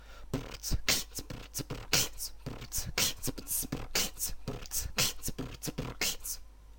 Оцените Хэндклэп..)
Бит взял такой ----> brr t kch t brr t brr kch t
желательно, чтобы хендклеп звучал не "кхи", а "кхы"(^^), но для твоего стажа он неплохо звучит:)